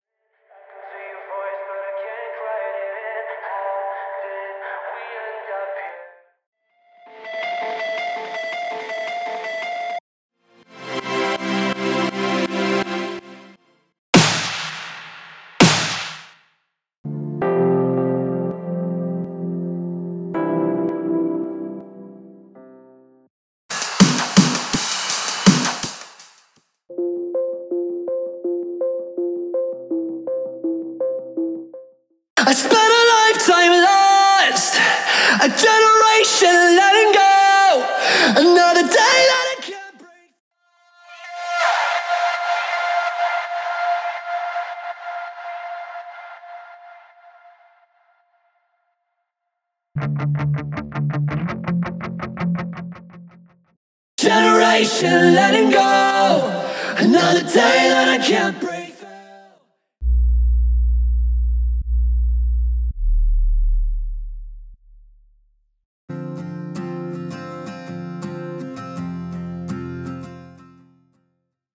Studio Acoustic Guitar Stem
Studio Leading Vocals Stem
Studio Piano Keys Stem
Studio Synths Stem